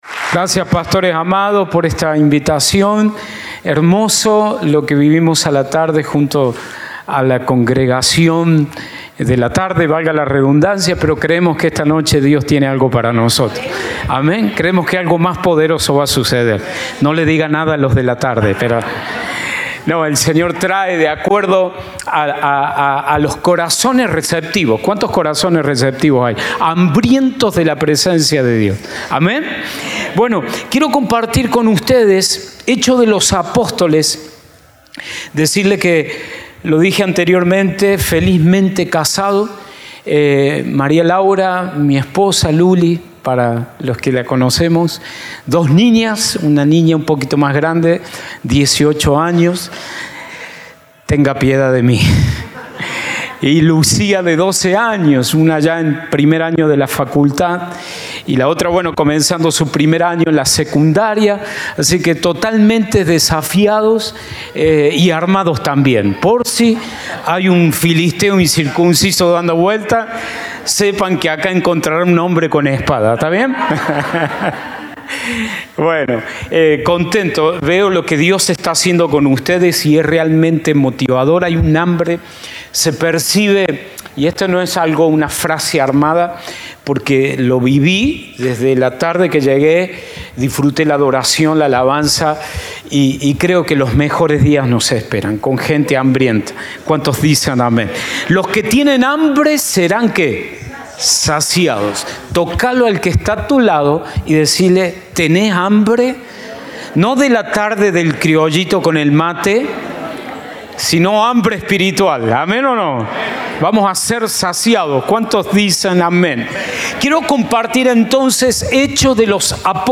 Compartimos el mensaje del Domingo 23 de Junio de 2024